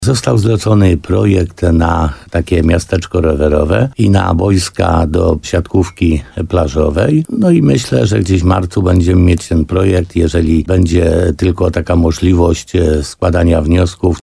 – Jest też plan na zmiany w tzw. parku Almatur – mówi wójt gminy Laskowa Piotr Stach.